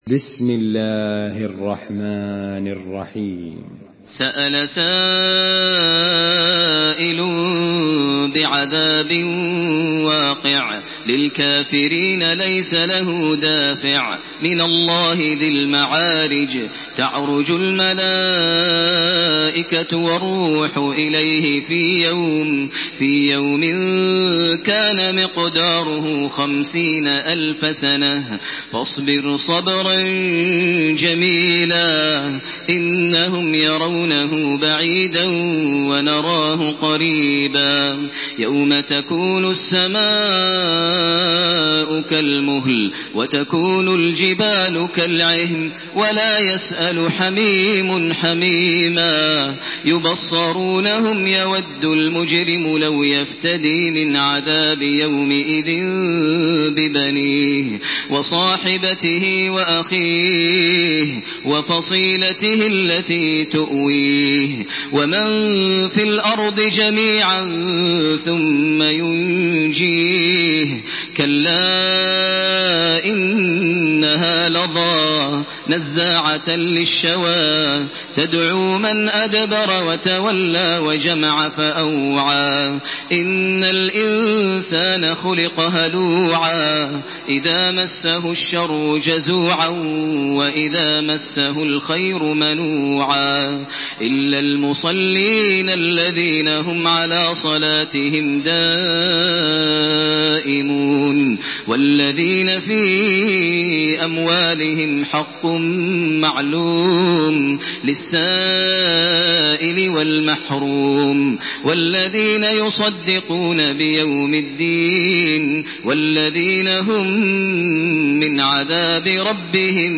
تراويح الحرم المكي 1428
مرتل